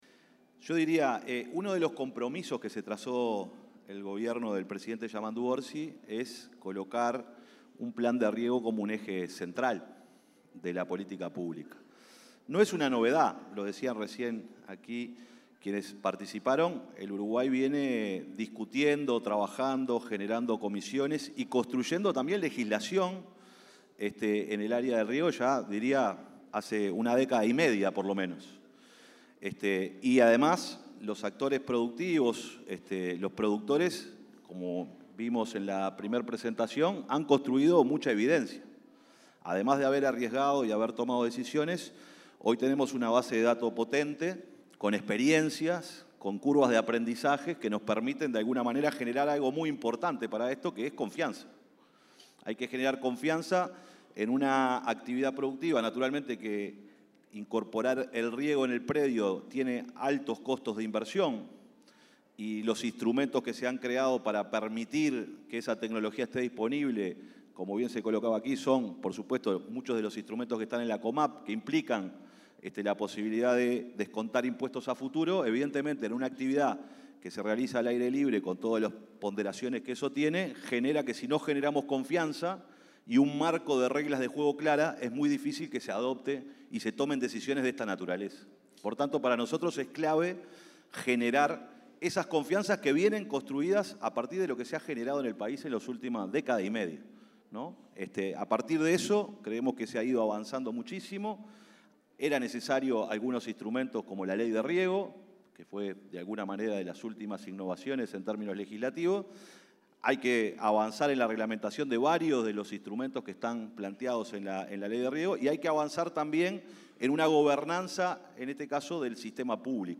Palabras del secretario de Presidencia, Alejandro Sánchez
Palabras del secretario de Presidencia, Alejandro Sánchez 09/09/2025 Compartir Facebook X Copiar enlace WhatsApp LinkedIn La 120.ª Exposición Internacional de Ganadería y Muestra Agroindustrial y Comercial Rural del Prado fue el ámbito en el que el secretario de la Presidencia, Alejandro Sánchez, disertó sobre riego en Uruguay y la creación de una comisión interministerial que trabajará en el tema.